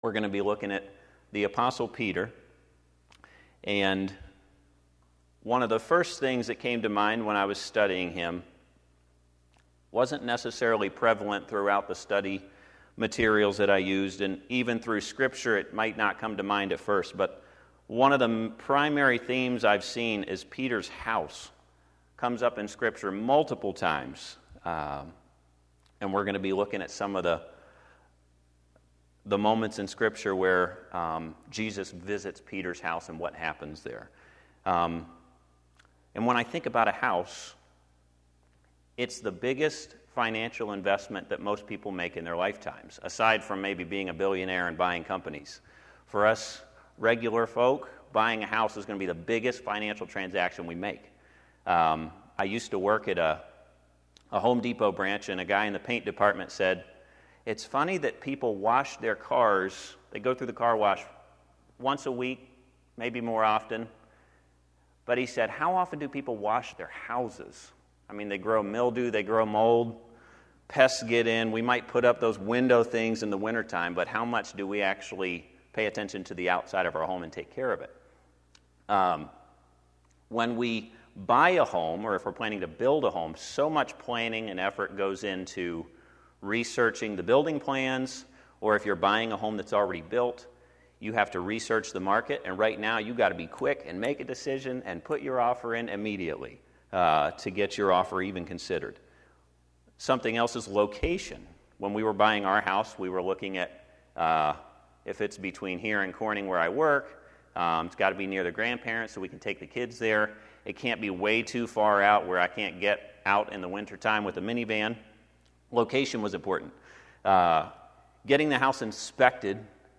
The Apostle Peter Sunday PM